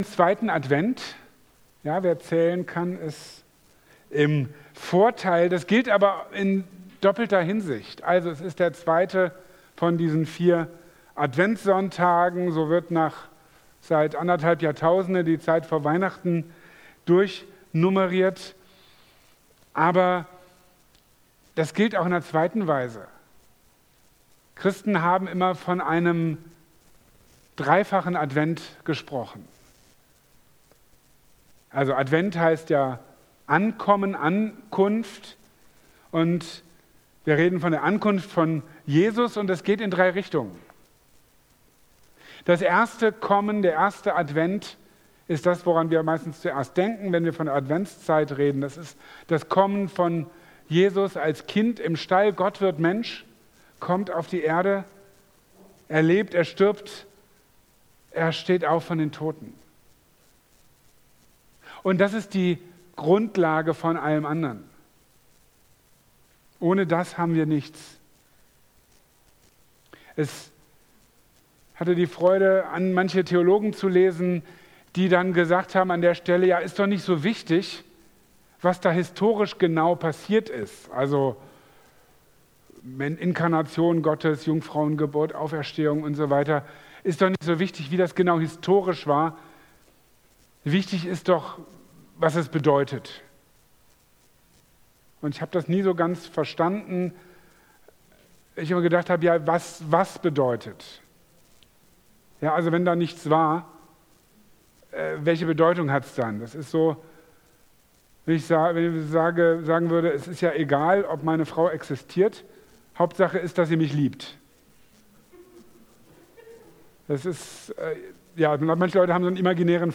Seht auf und erhebt eure Häupter | Marburger Predigten